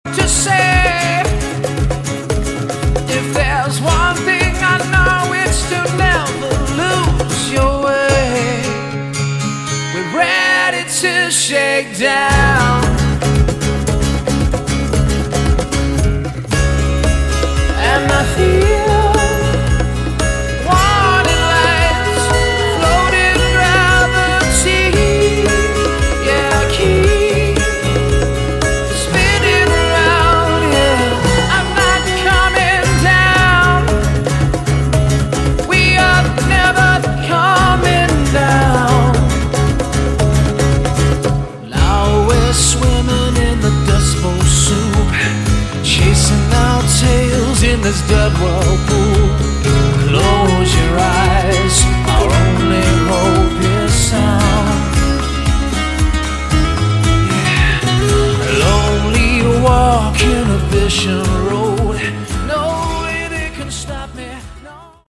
Category: Modern Hard Rock
bass
vocals
guitars
drums
acoustic